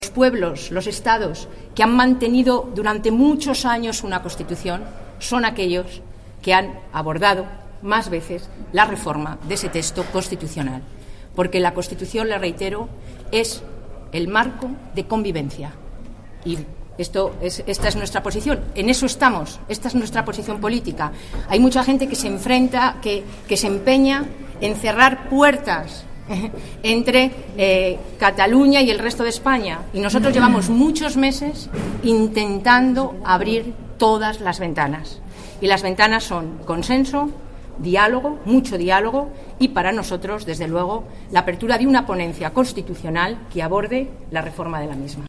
Declaraciones de Soraya Rodríguez el 29/10/2013 sobre Cataluña